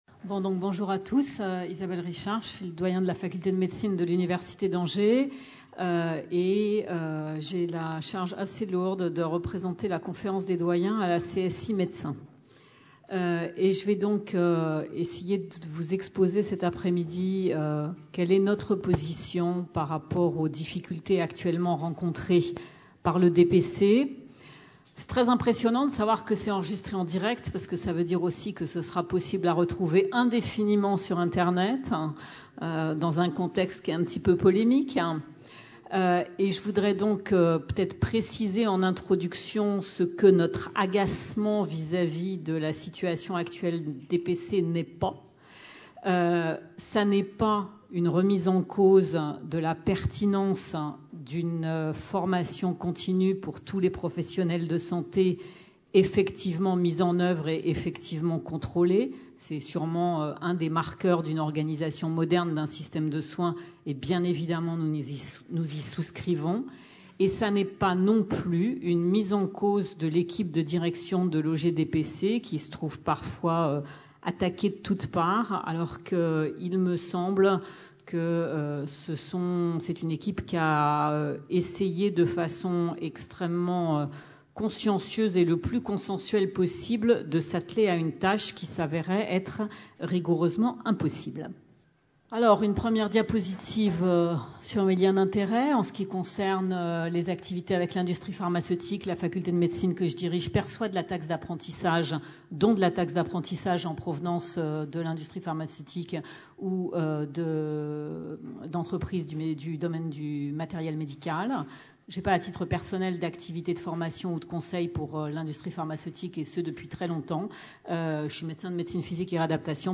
Conférence enregistrée lors du colloque DPC-SU le vendredi